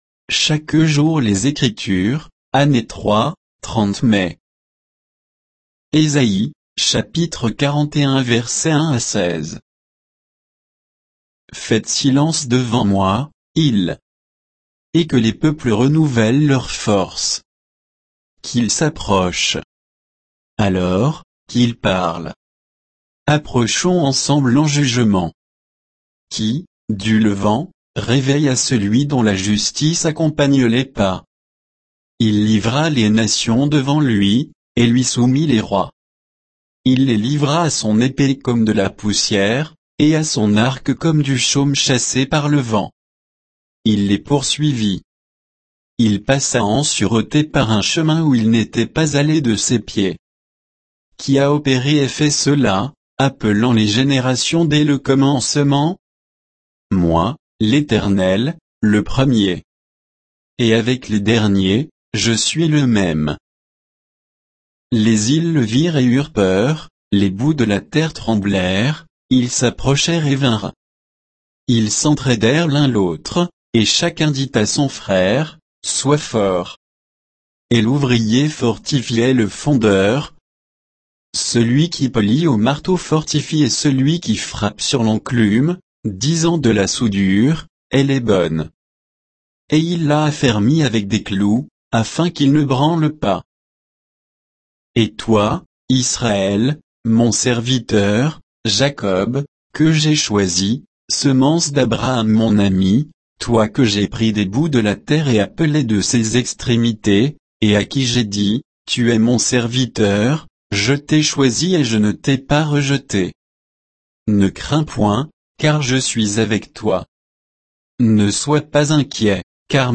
Méditation quoditienne de Chaque jour les Écritures sur Ésaïe 41